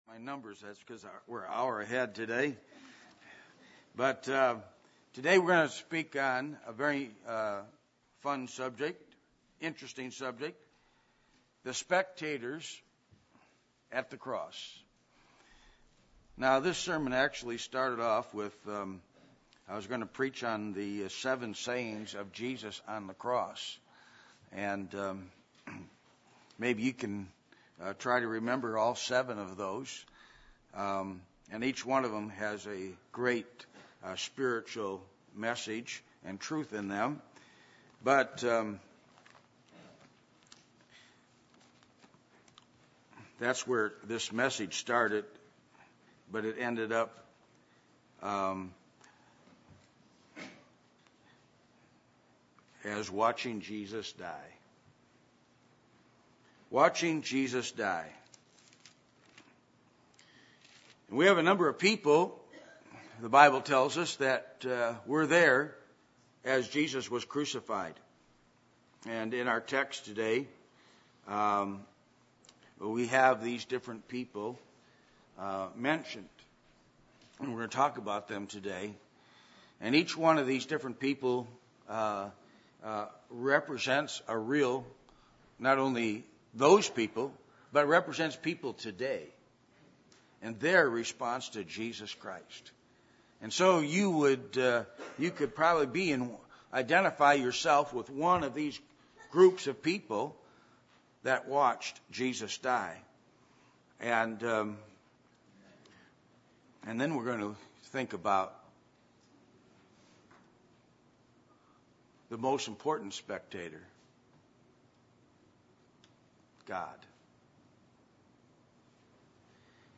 Luke 23:20-56 Service Type: Sunday Morning %todo_render% « Three Reasons For Disastrous Decisions A Biblical Look At Clothing